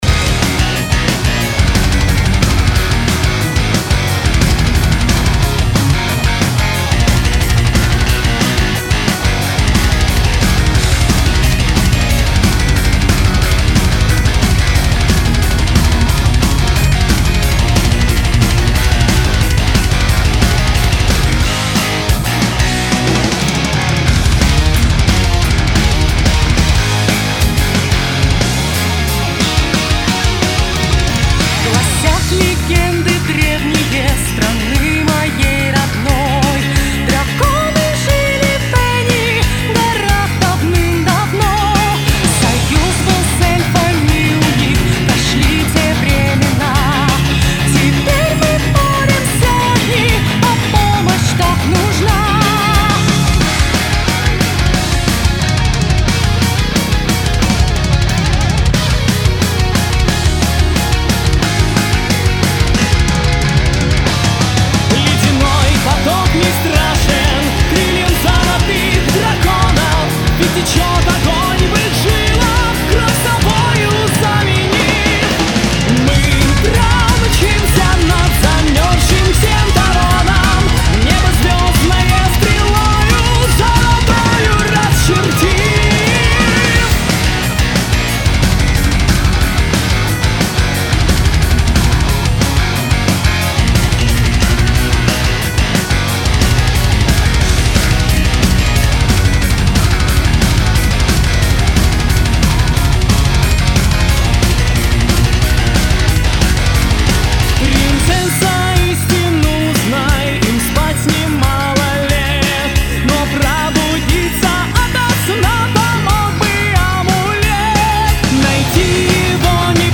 Power Metal